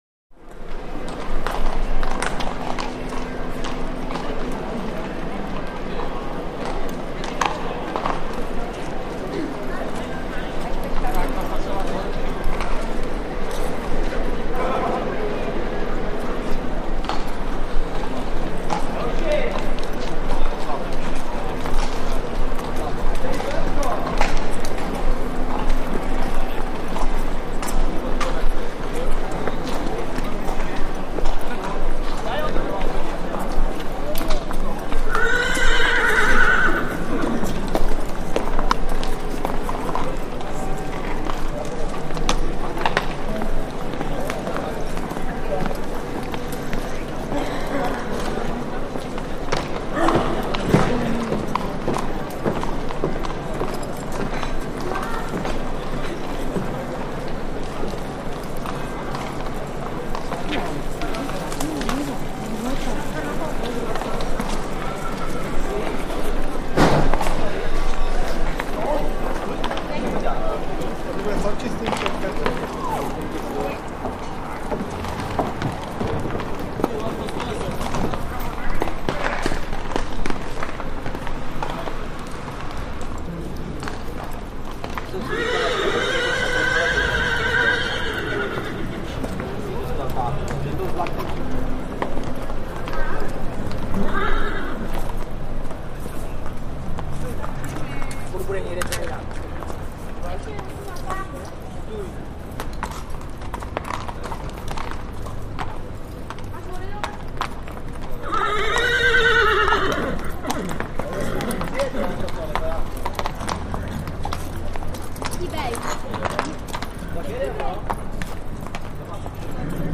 Street BG
City Street 02, Light Activity; City Street With Distant Bell At Front, Walla, Horses Hooves On Cobbled Stone, Whinnies, Shouts Etc.